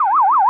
alarm_siren_loop_02.wav